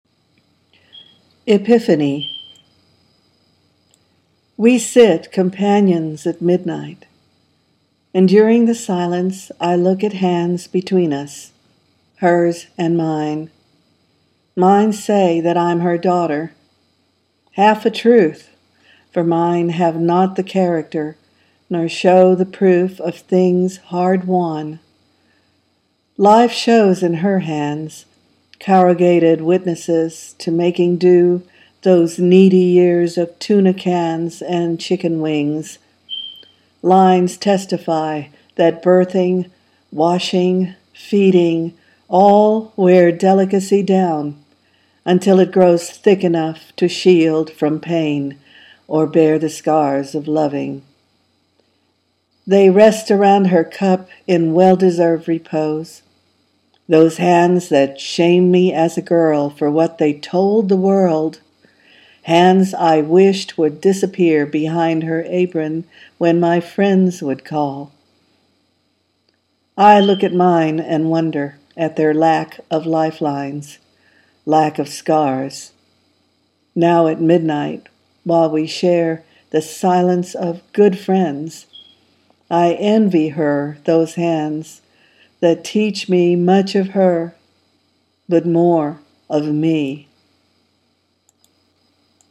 a tribute poem